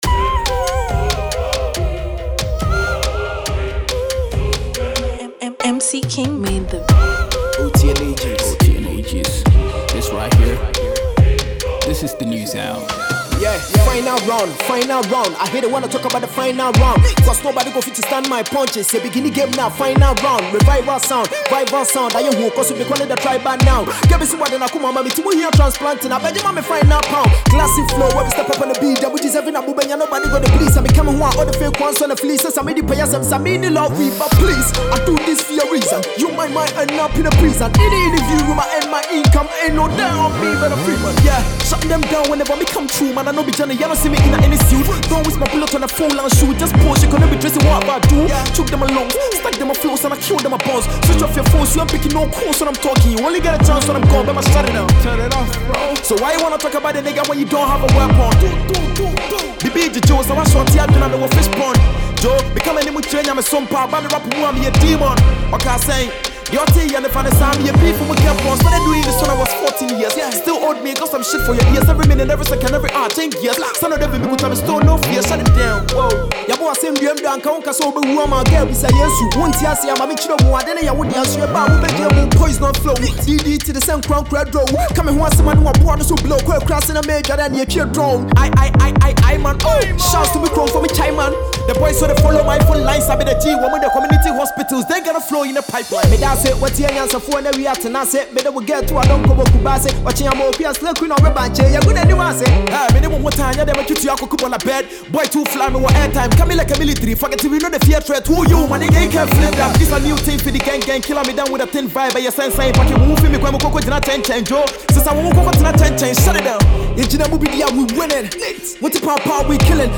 Ghanian,Rappers